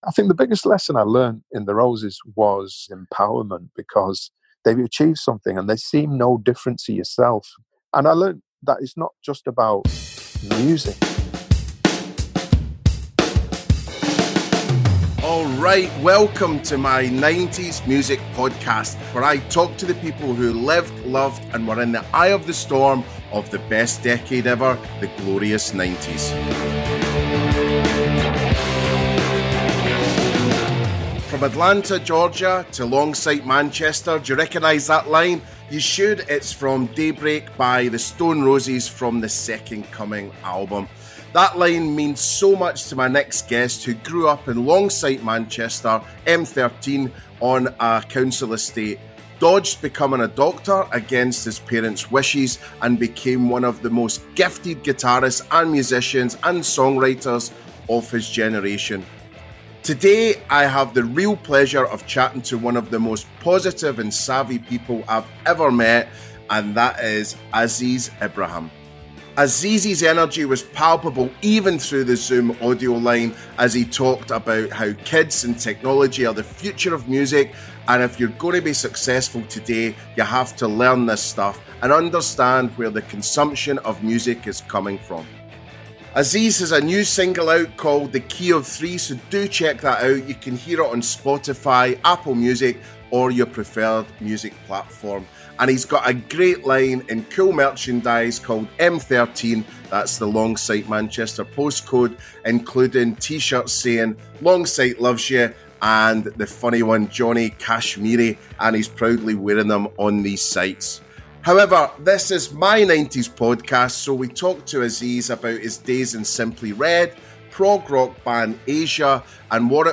Today I had the real pleasure of chatting to one of the most positive and savvy people I have met in a long time and that man is Aziz Ibrahim. Aziz’z energy was palpable even through the zoom audio line as he talked about how kids and technology are the future of music and if you are going to be successful today you have to learn this stuff and understand where the consumption of music is going.